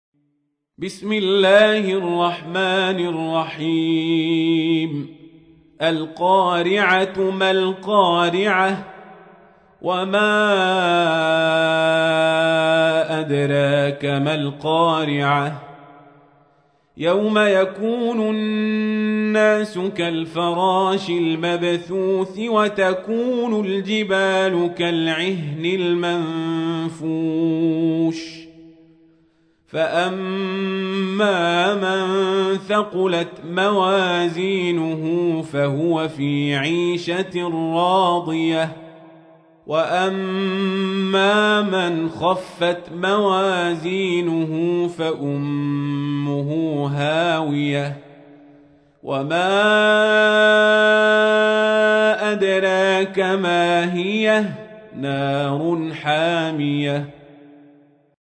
تحميل : 101. سورة القارعة / القارئ القزابري / القرآن الكريم / موقع يا حسين